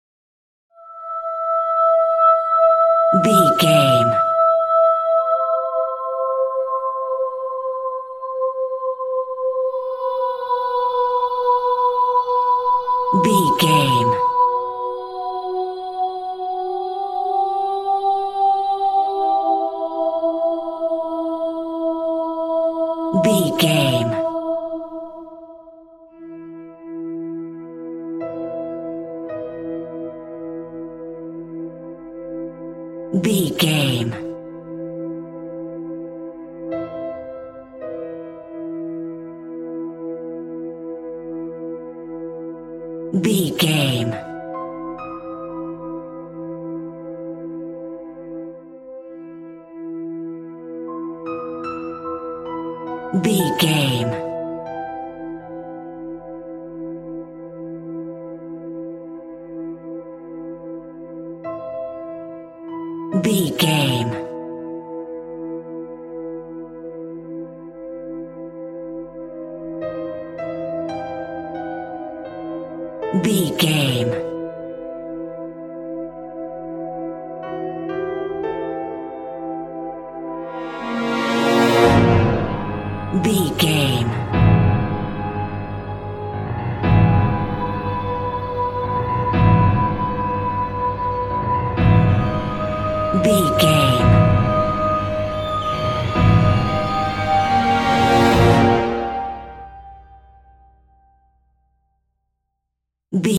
Dramatic Music.
Aeolian/Minor
ominous
suspense
haunting
eerie
synthesizer
piano
brass
horror
cymbals
gongs
viola
french horn trumpet
taiko drums
timpani